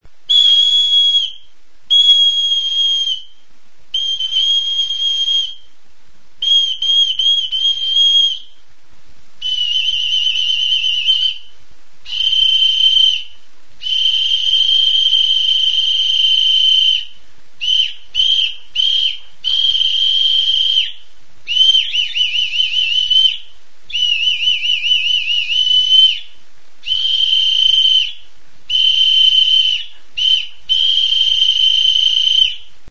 Aerophones -> Flutes -> Fipple flutes (one-handed)
Erakusketa; hots-jostailuak
Metalezko txaparekin egindako txifloa edo zulorik gabeko flauta sinplea da.